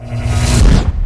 metabolize_fire.wav